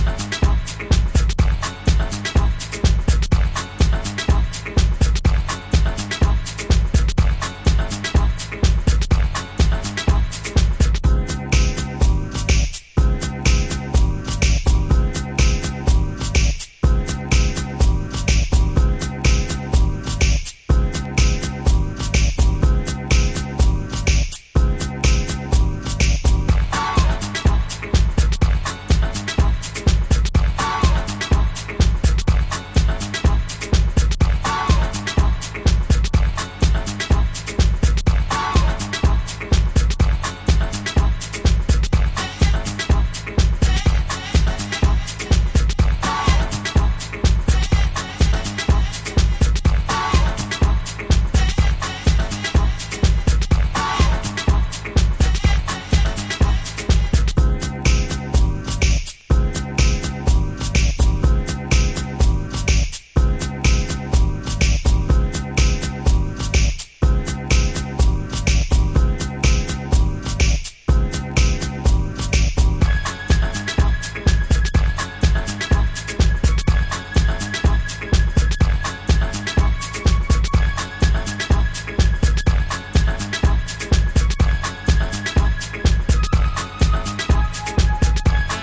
Early House / 90's Techno